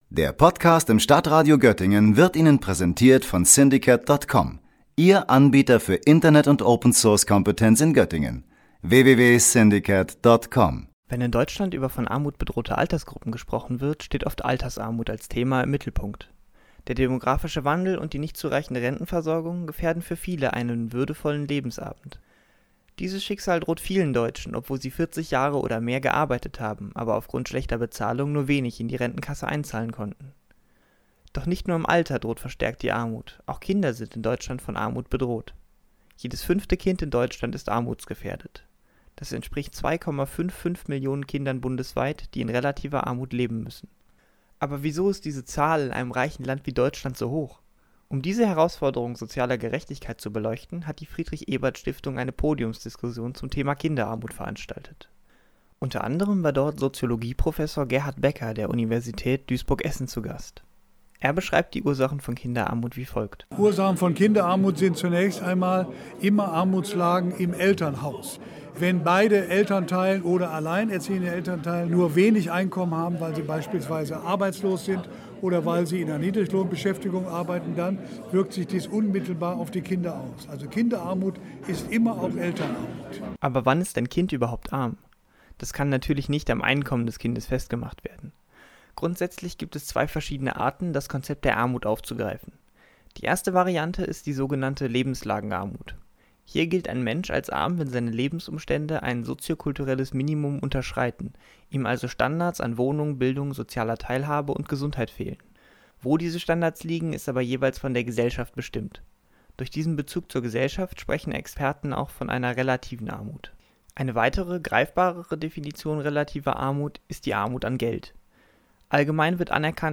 Beiträge > Kinderarmut – Podiumsdiskussion über Ursachen und akuten Handlungsbedarf - StadtRadio Göttingen